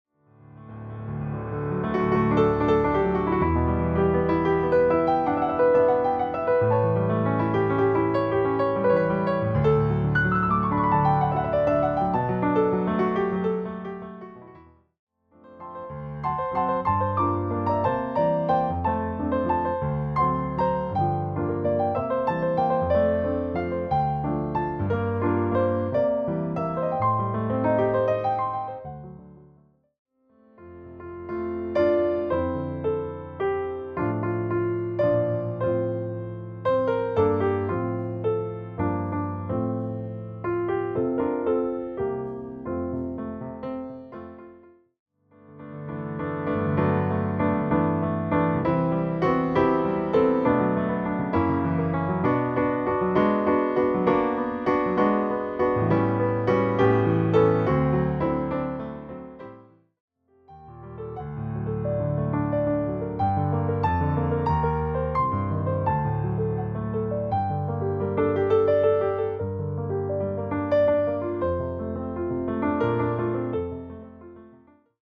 offers an easygoing, relaxed take on classic holiday music.